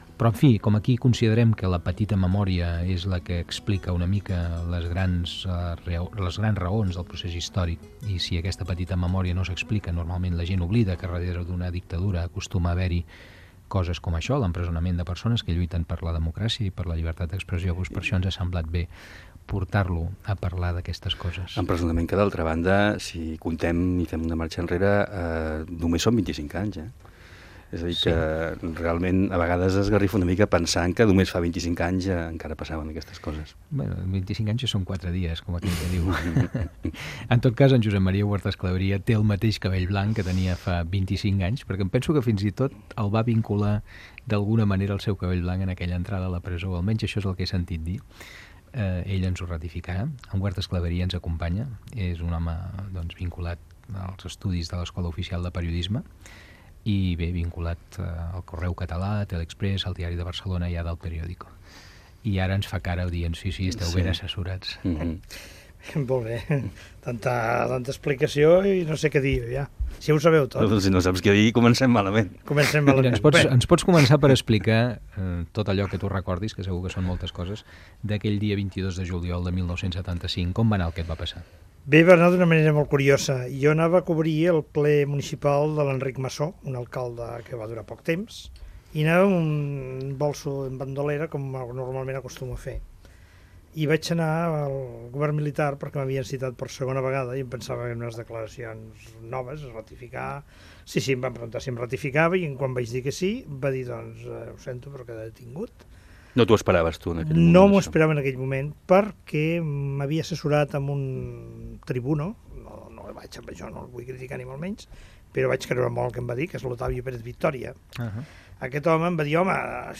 Fragment d'una entrevista al periodista Josep Maria Huertas Claveria. Feia 25 anys que havia entrat a la presó el 22 de juliol de 1975.
Fragment extret de l'arxiu sonor de COM Ràdio.